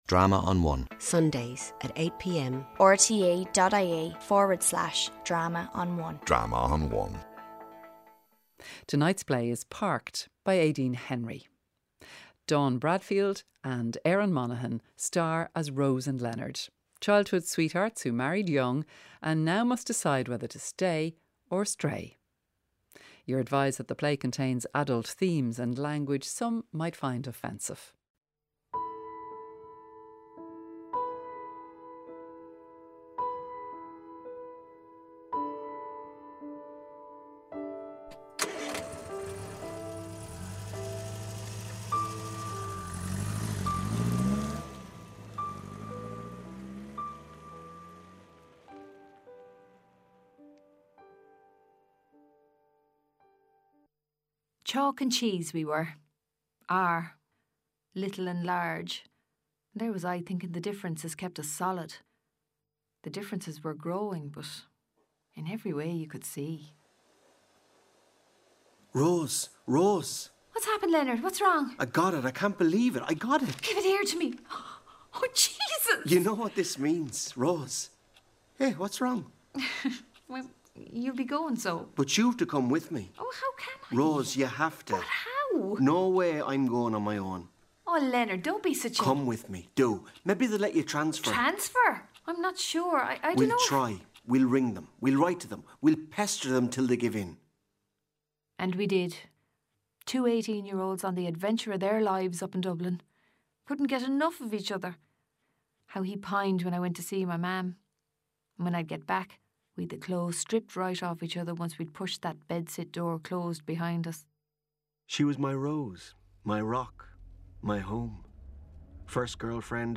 RTÉ Radio Drama's audio theatre department has for decades proudly brought audiences the very best dramatic writing and performances for radio from Ireland.